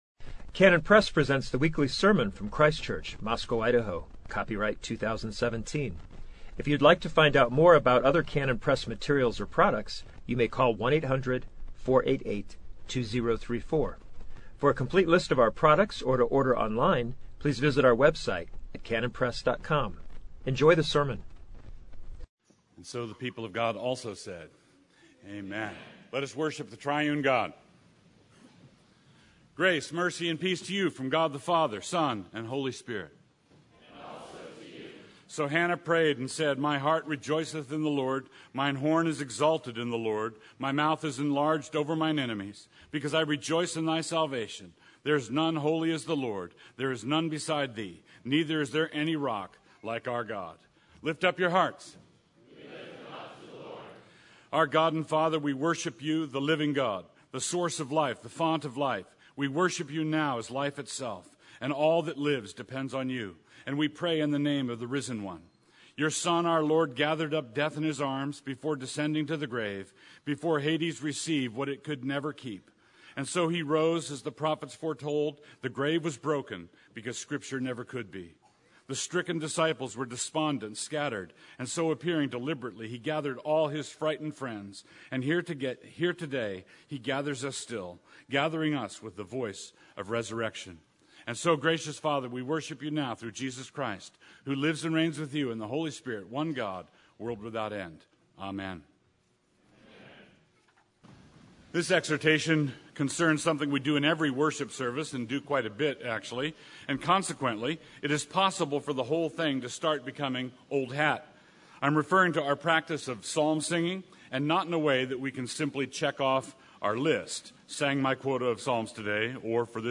Christ Church on August 27, 2017